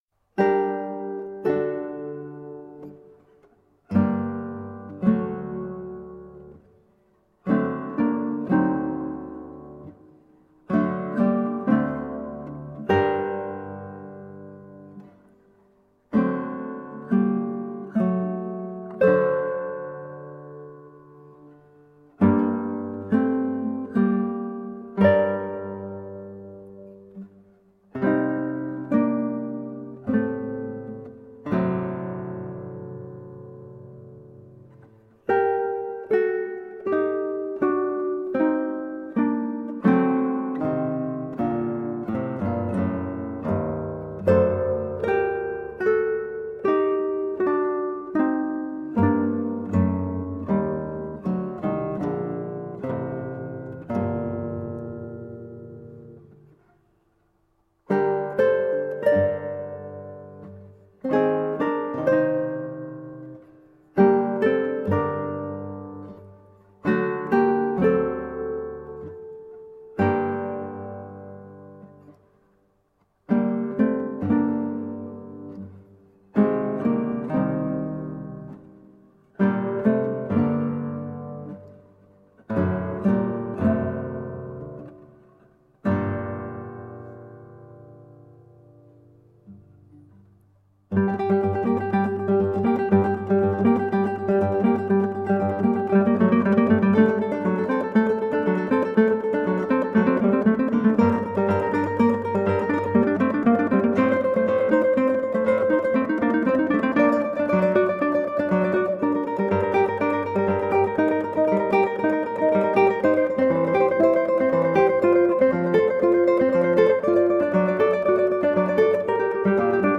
Colorful classical guitar.
Classical, Baroque, Instrumental
Classical Guitar, Ukulele